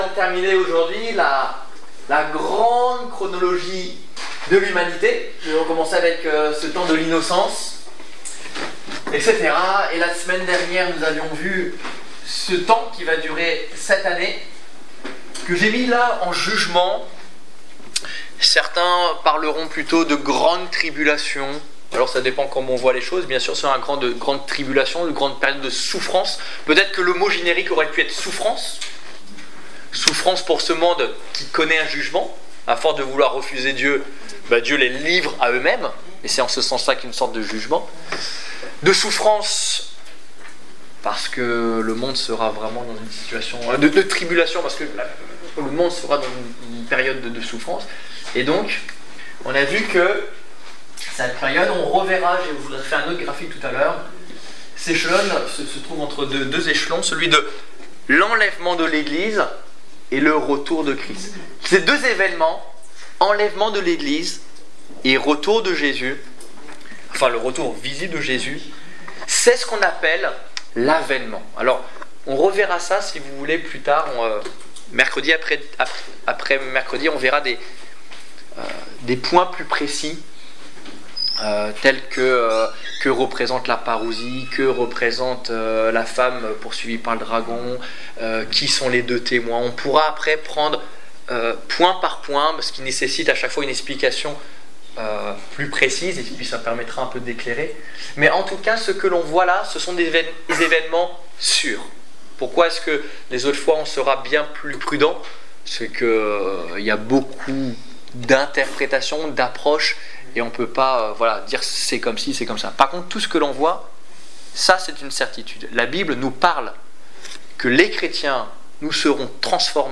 Étude biblique du 22 avril 2015